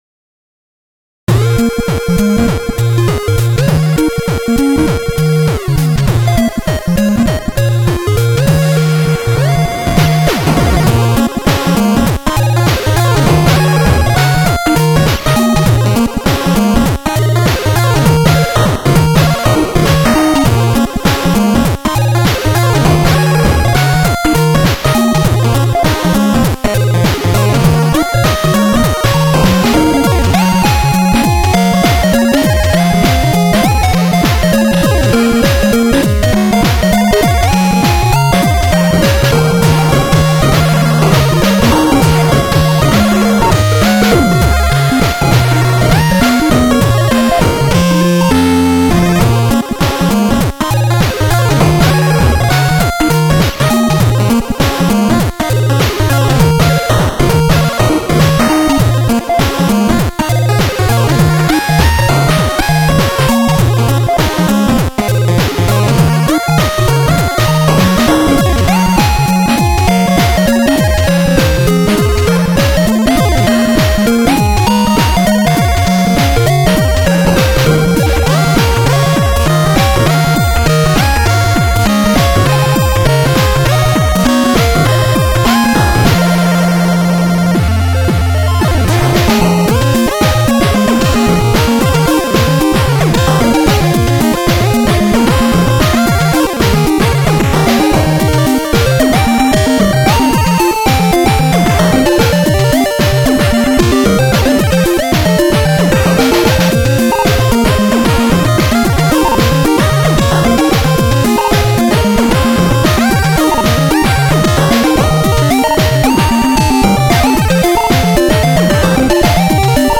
※ (　　)内は、使用音源チップです。
(2A03)